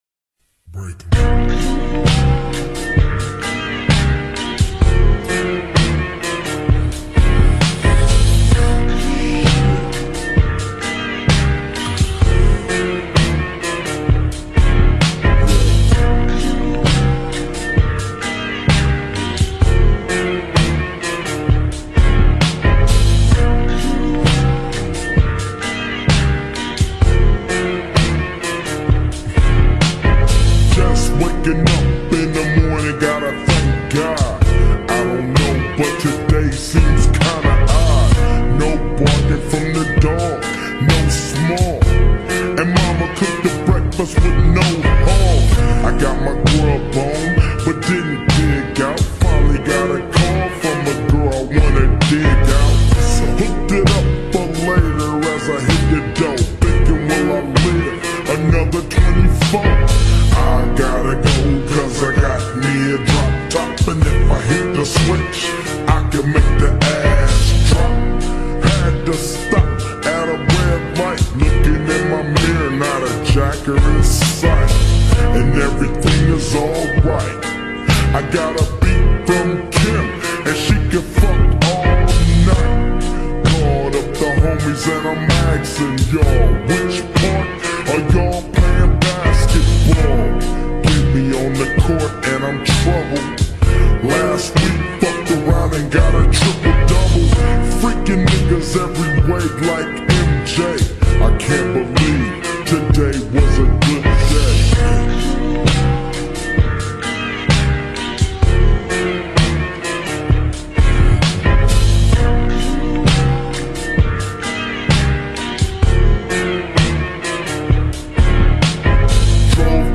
2025-03-18 18:09:57 Gênero: Hip Hop Views